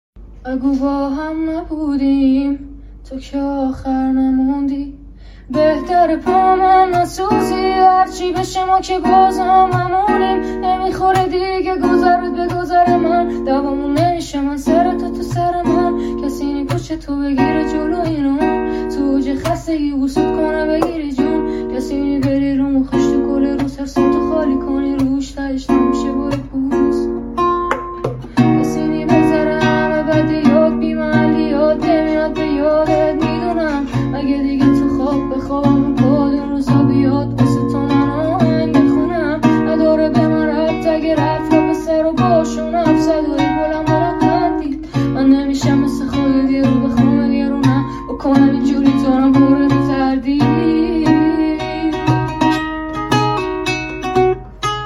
Guitar version